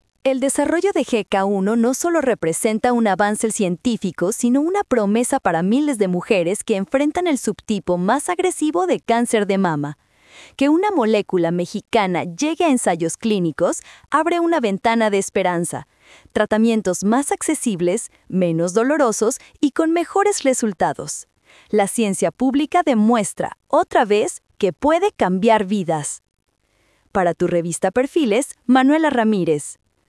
🎙 Comentario Editorial